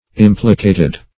implicated.mp3